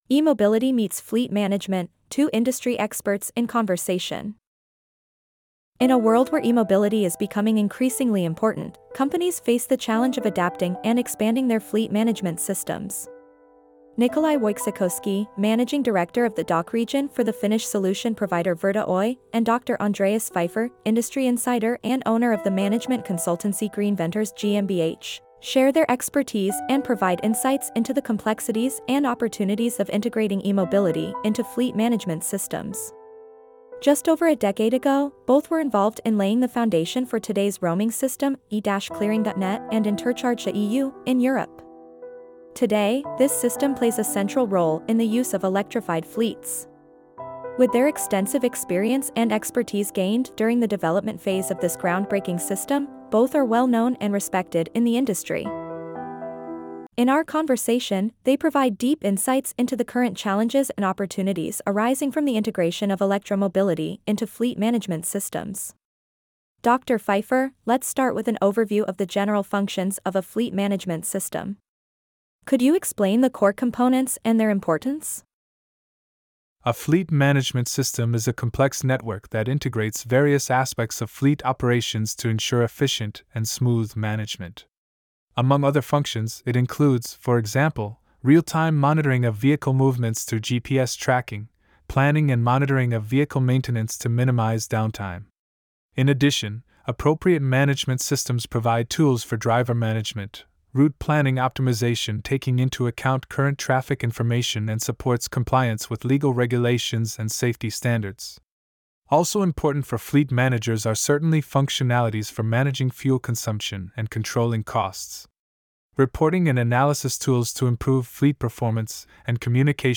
Fleet management meets emobility: Two industry experts in conversation